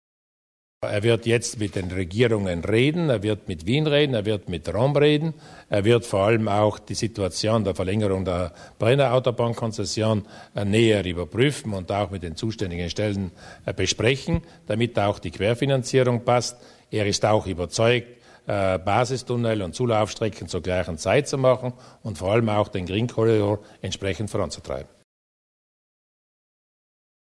Landeshauptmann Luis Durnwalder über das Treffen mit Koordinator Cox